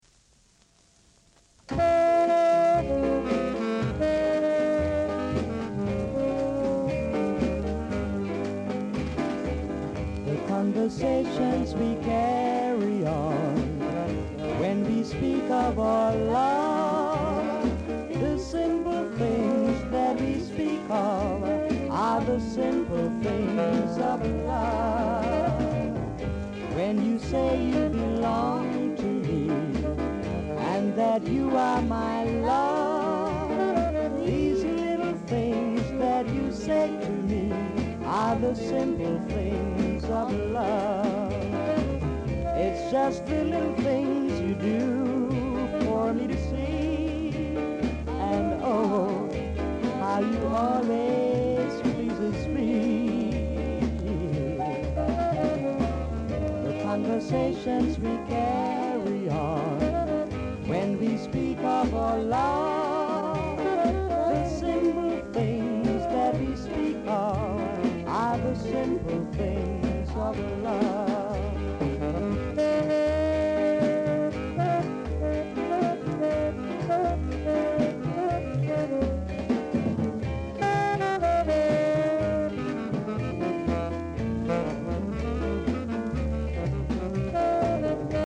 Vocal Duet
Very rare! great ska & soul vocal!